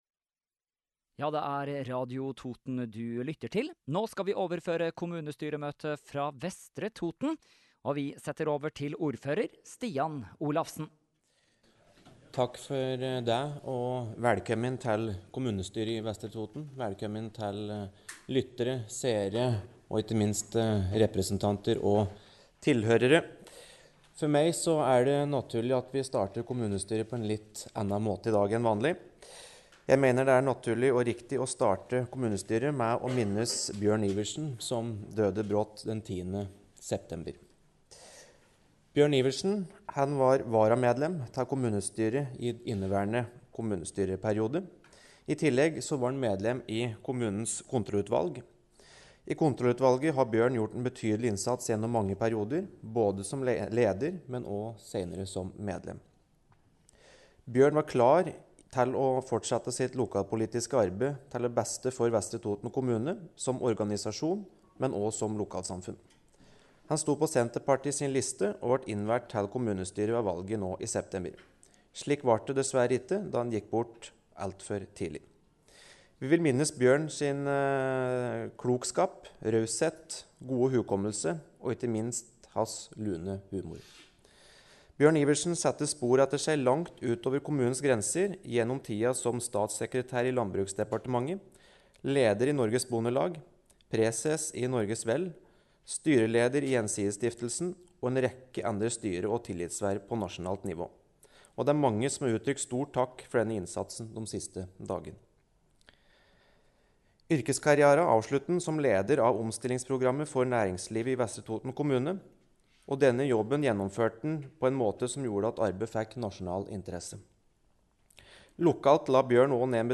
Kommunestyremøte i Vestre Toten 28. september kl. 09:00 – Lydfiler lagt ut | Radio Toten